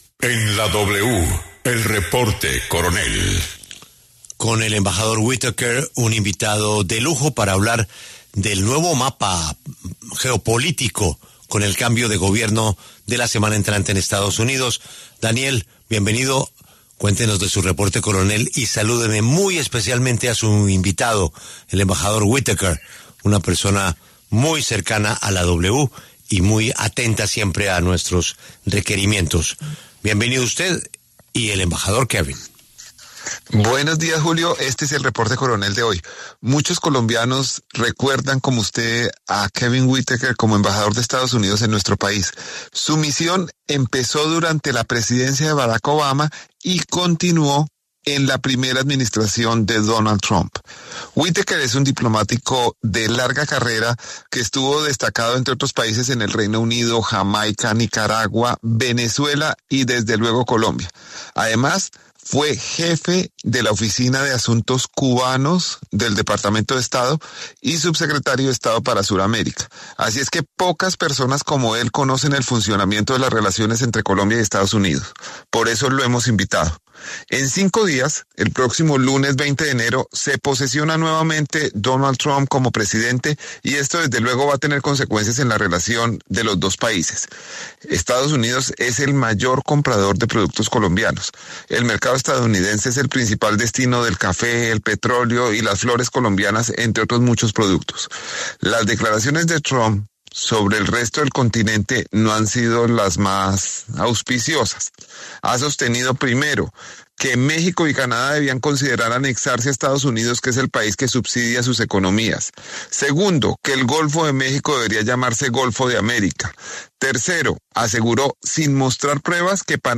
Exembajador Kevin Whitaker habla sobre el regreso de Trump y las relaciones Colombia–EE.UU.